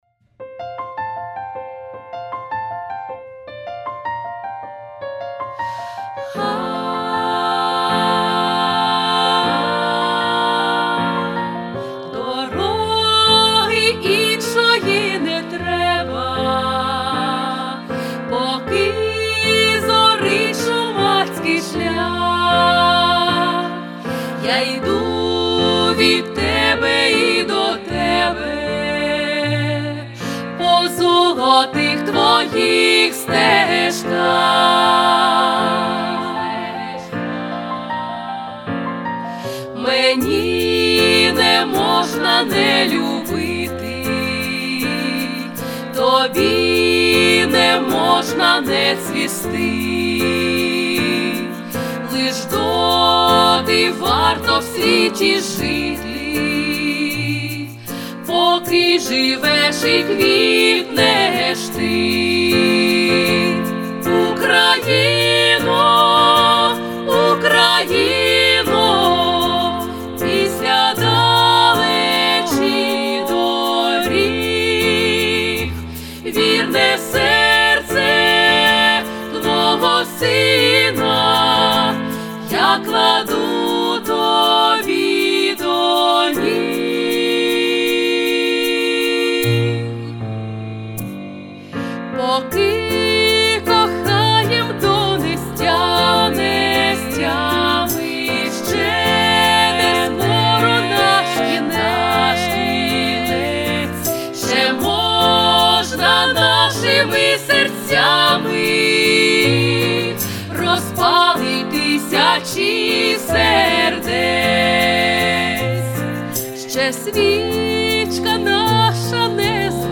3. Готове аудіо демо (всі партії разом).
Ноти для тріо